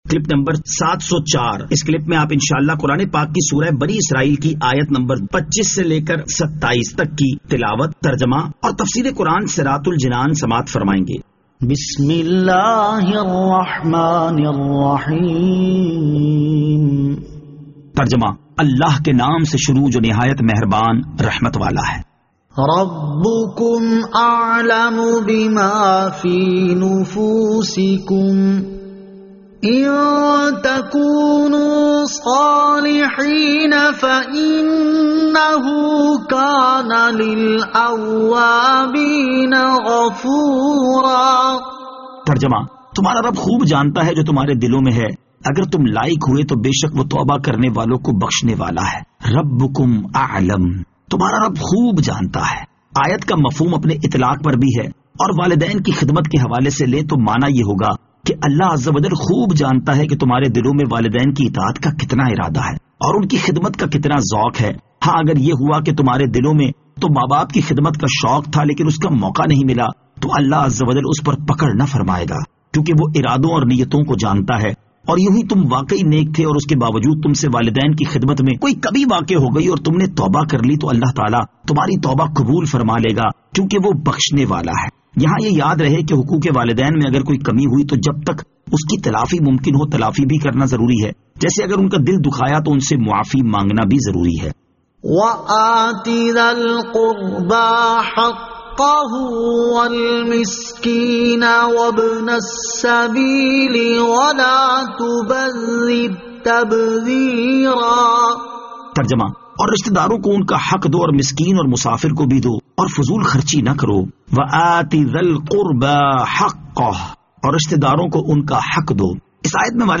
Surah Al-Isra Ayat 25 To 27 Tilawat , Tarjama , Tafseer